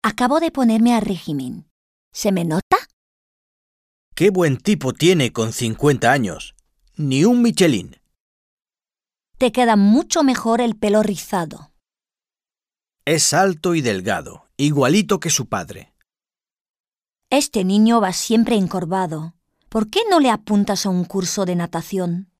Un peu de conversation - L'aspect physique